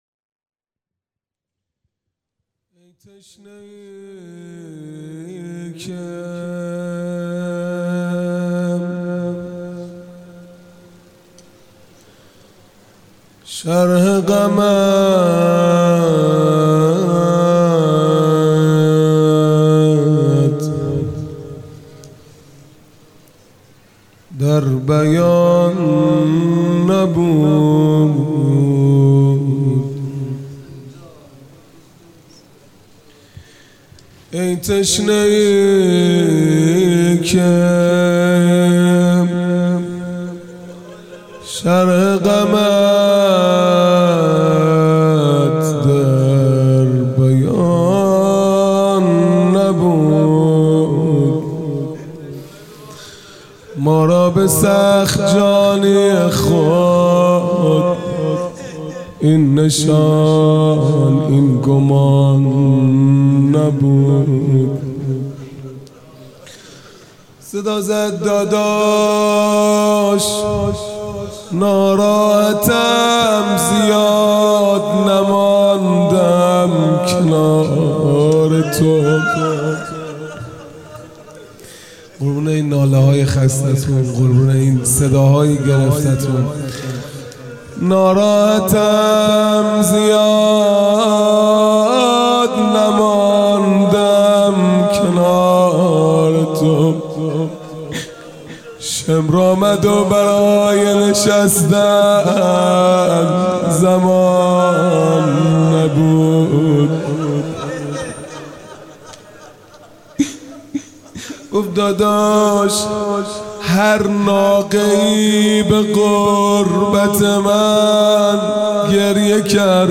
خیمه گاه - هیئت بچه های فاطمه (س) - روضه | ای تشنه ای که شرح غمت در بیان نبود | ۶ مرداد ماه ۱۴۰۲